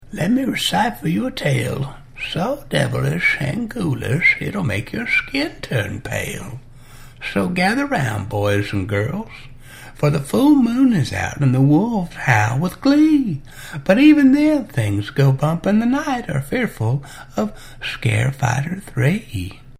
sf3whistle.mp3